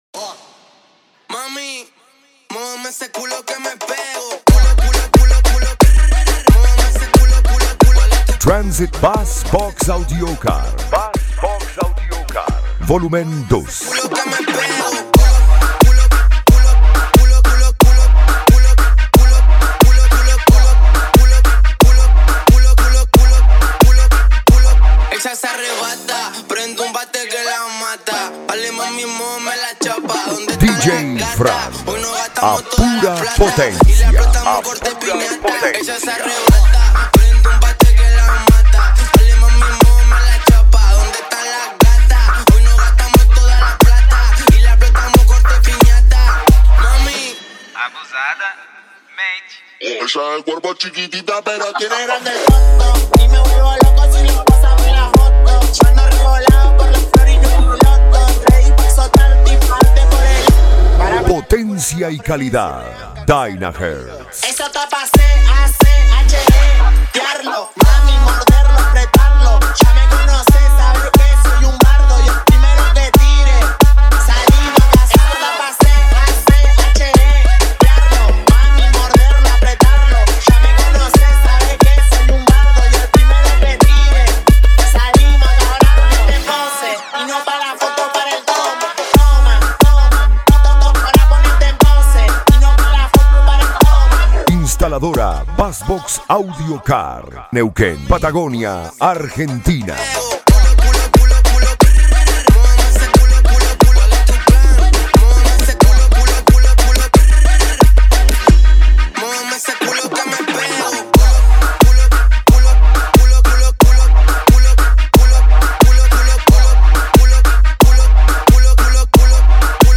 Bass
Variados